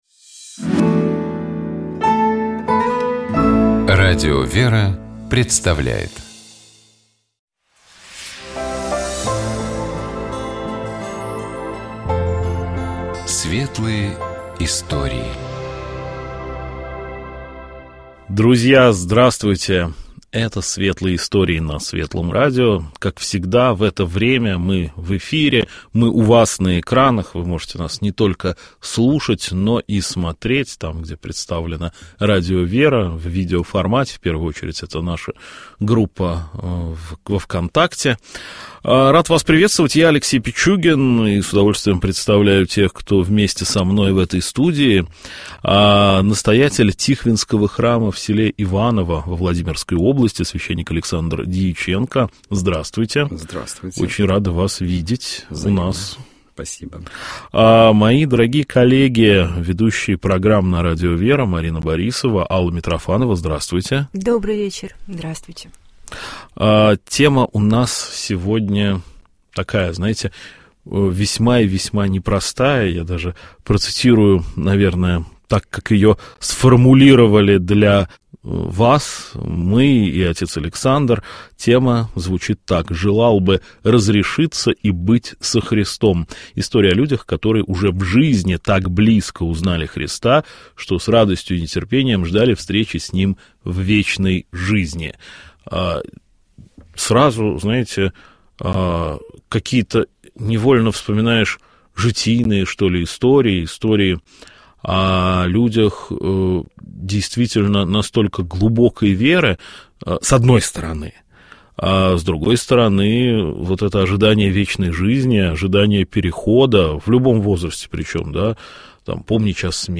Ведущая программы